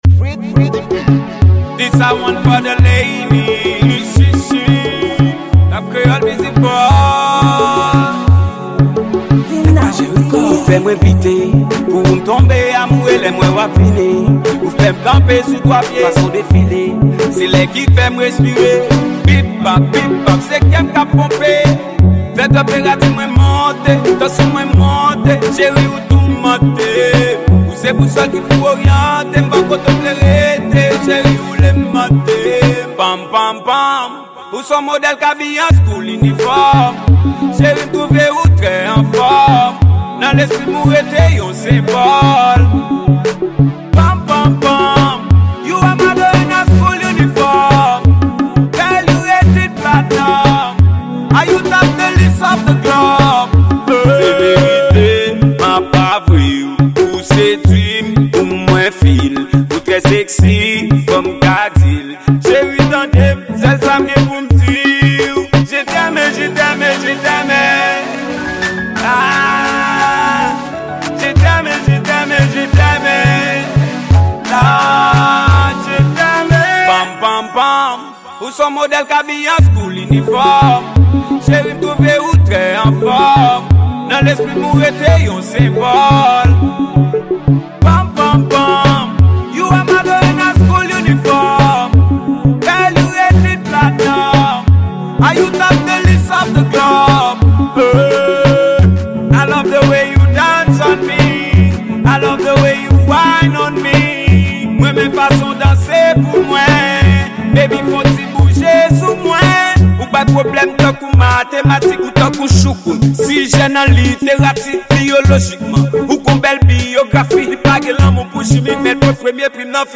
Genre: Dance Hall.